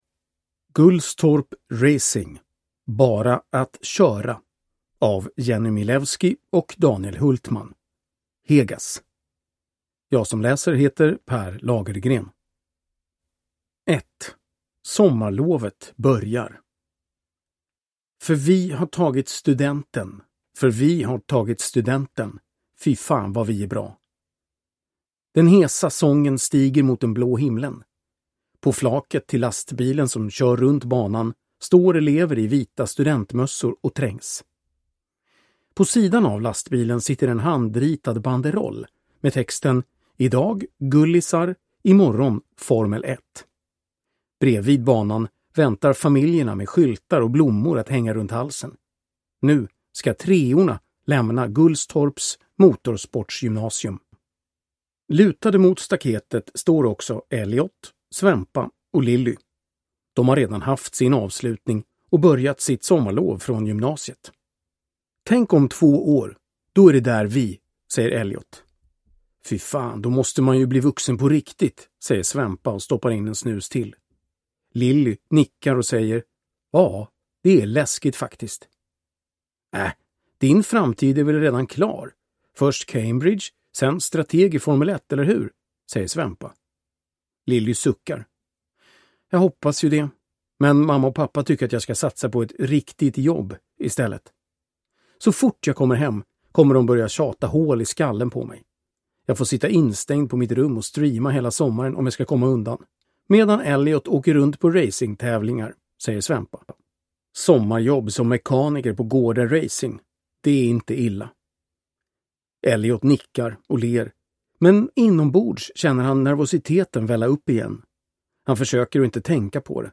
Bara att köra – Ljudbok